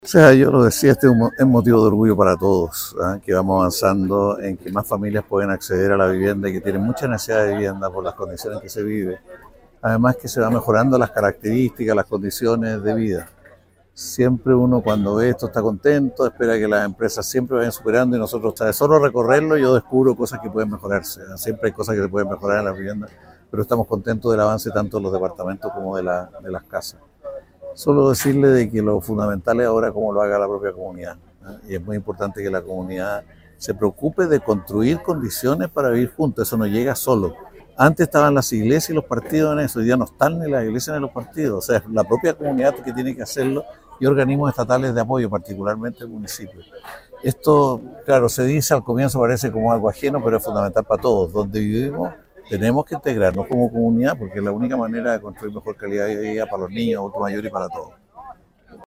El sueño de la vivienda propia para 260 familias de Melipilla ya es realidad, tras la ceremonia de entrega del proyecto habitacional “Sol Poniente de Melipilla”.